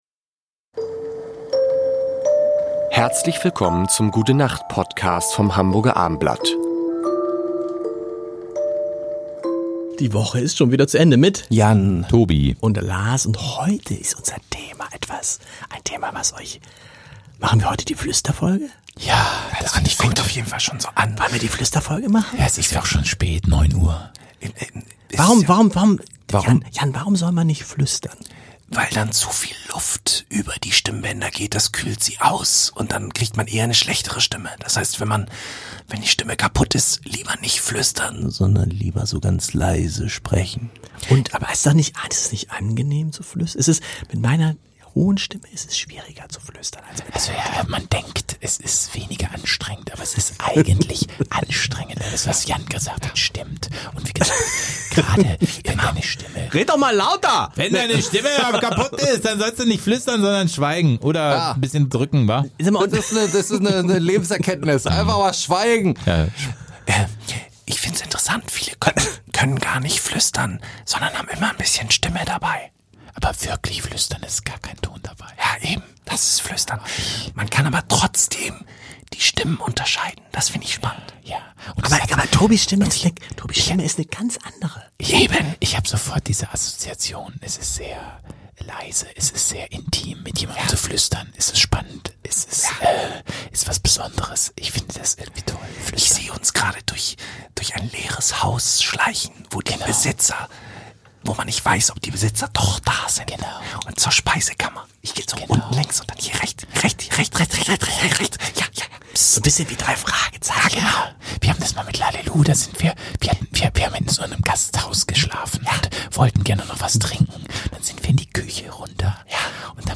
Die erste und sehr anstrengende Flüsterfolge.